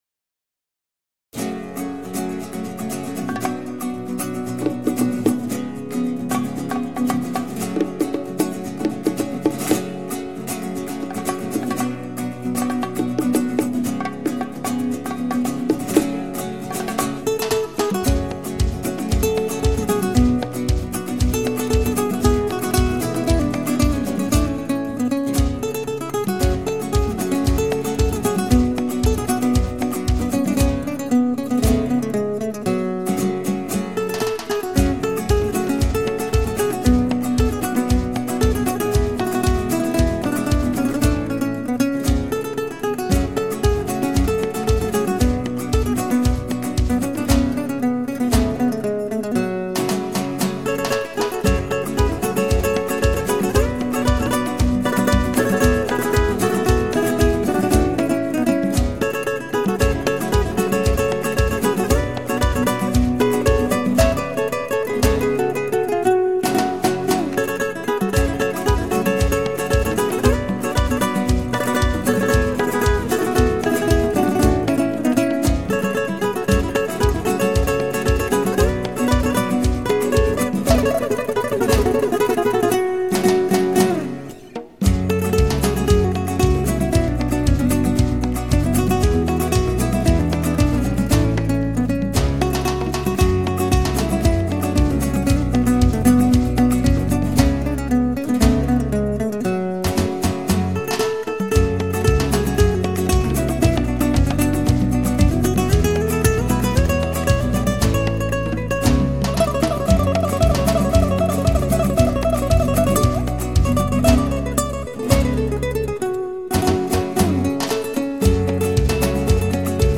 Latin Guitar
Acoustic Guitar